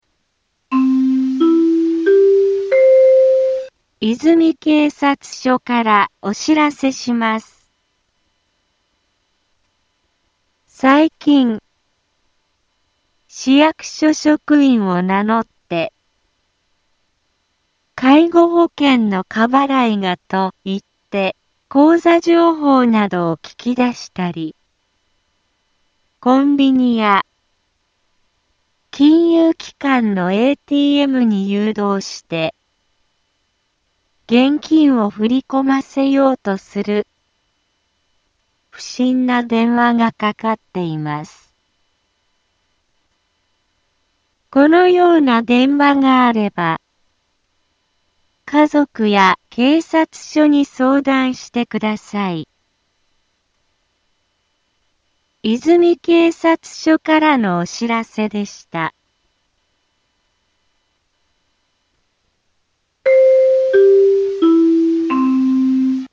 Back Home 災害情報 音声放送 再生 災害情報 カテゴリ：通常放送 住所：大阪府和泉市府中町２丁目７−５ インフォメーション：和泉警察署からお知らせします。 最近、市役所職員を名乗って、「介護保険の過払いが」と言って口座情報などを聞き出したり、コンビニや、金融機関のATMに誘導して、現金を振り込ませようとする、不審な電話がかかっています。